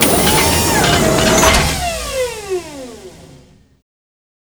extend.wav